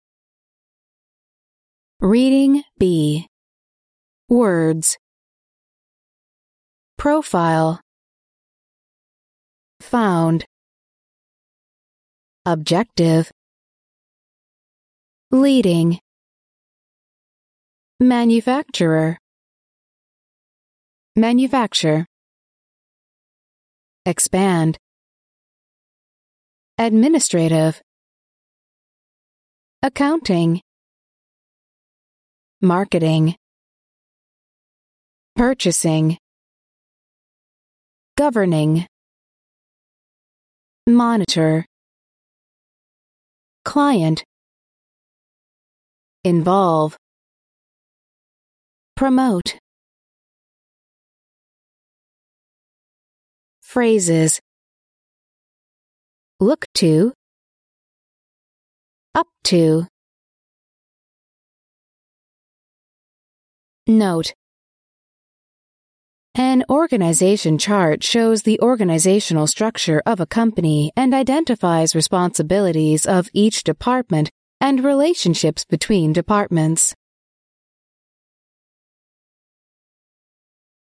第一册英语单词朗读录音